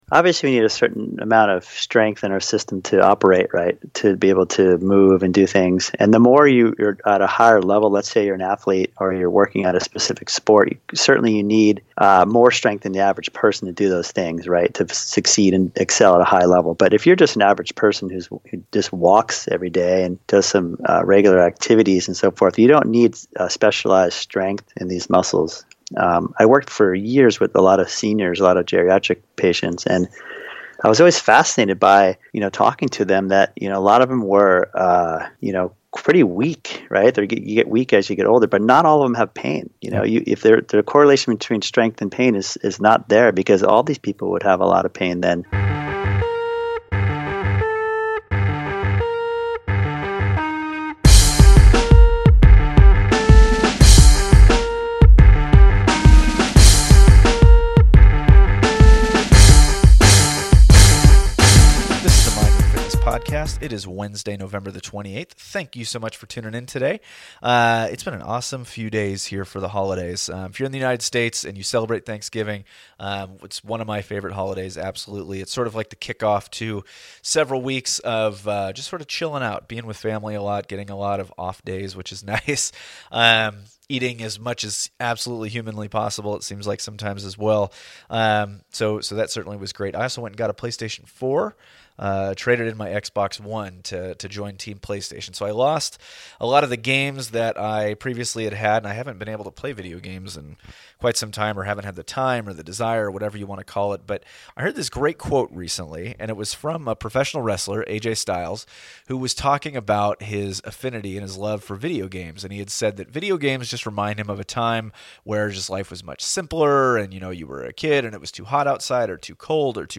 etc. This is honestly one of my absolute favorite interviews thus far.